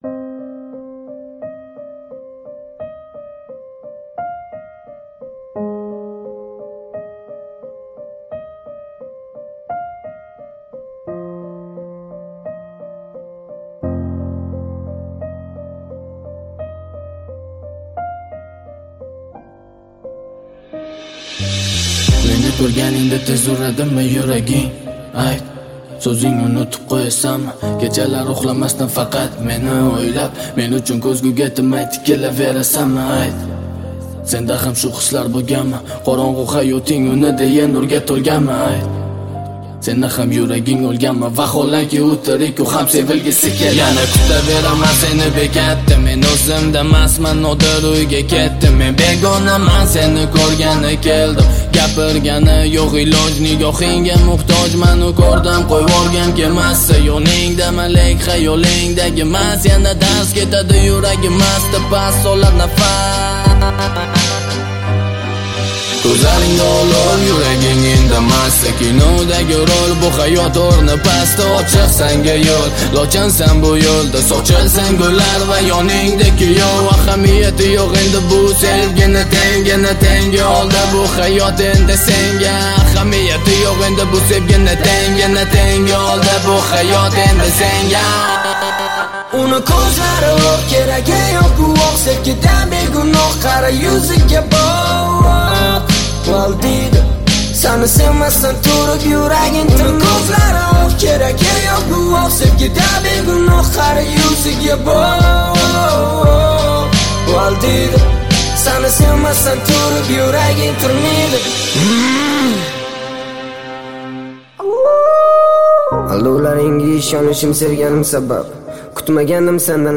Узбекский рэп